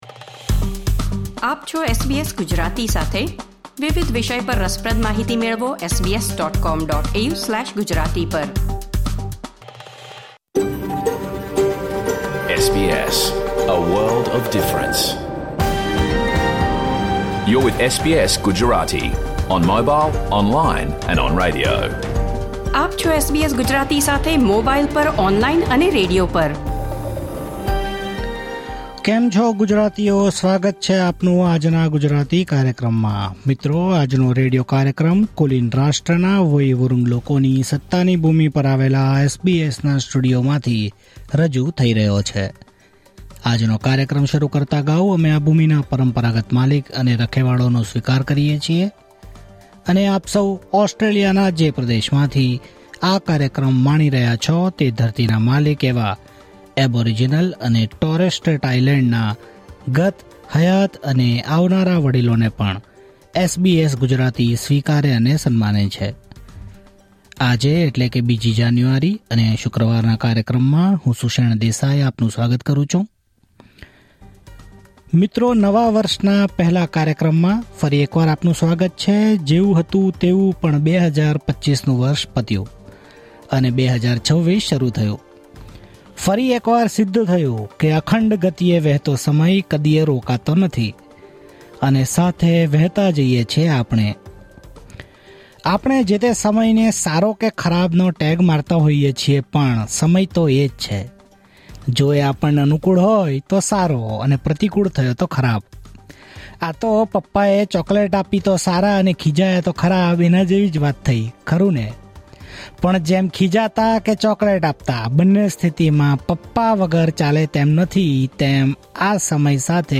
Catch the full episode of SBS Gujarati radio program